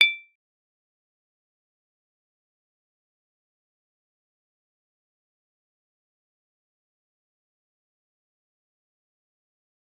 G_Kalimba-D7-f.wav